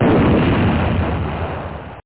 explosion1.mp3